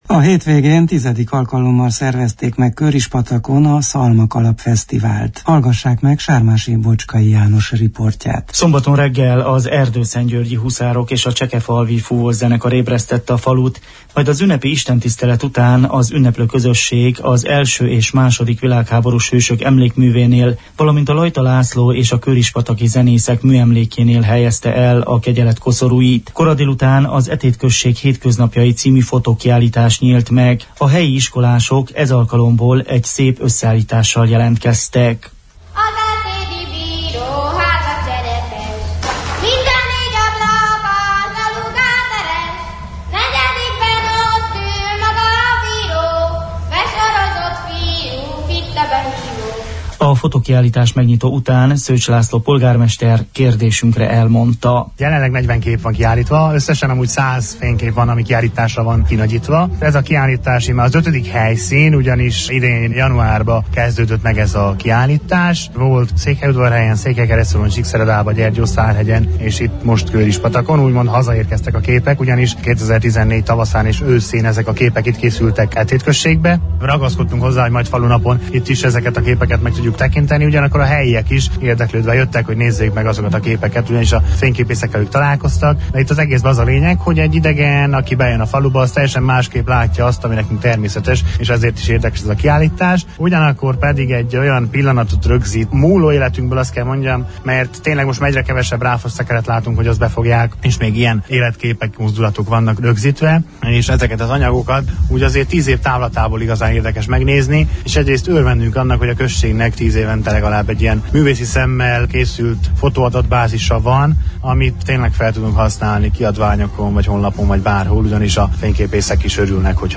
Szalmakalap Fesztiválon jártunk!